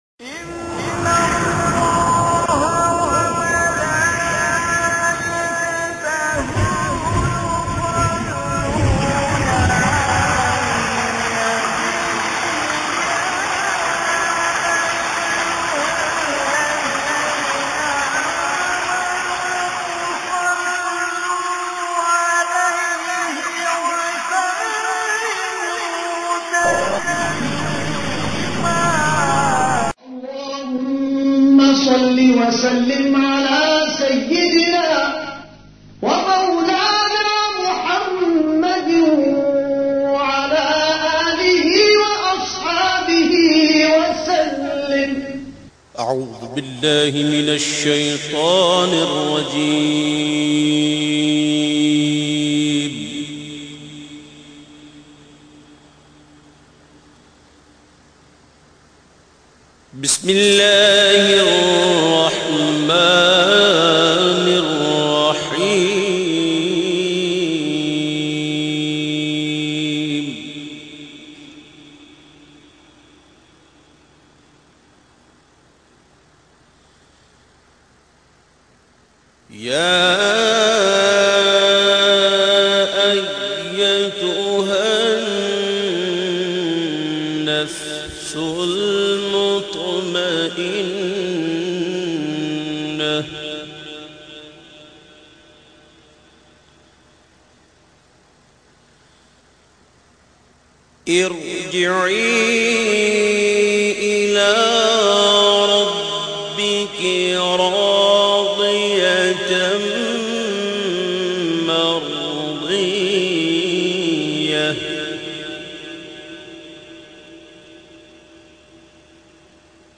Tilawat-e-Quran By Owais Raza Qadri
Download and Online Listen Some verses of Tilawat-e-Quran Pak in Voice of Bulbulay Madina Alhaj Owais Raza Qadri.
qiraat.mp3